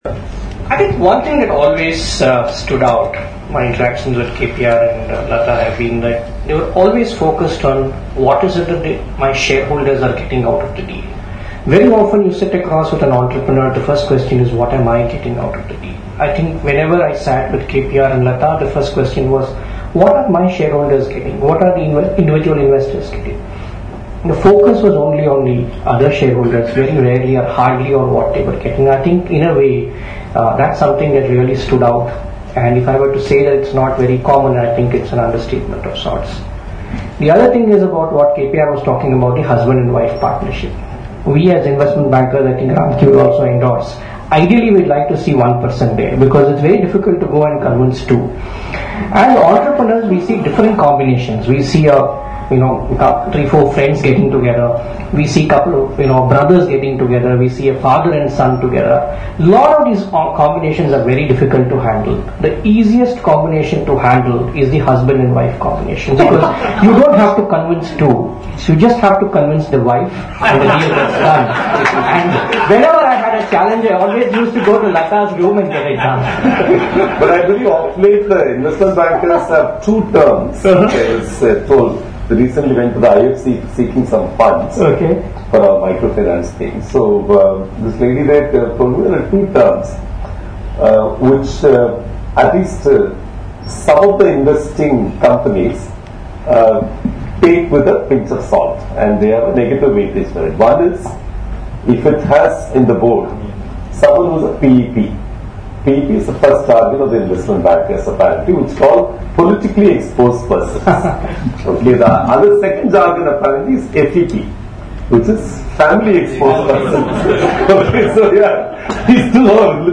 (Recorded on August 15, 2012 in Chennai.)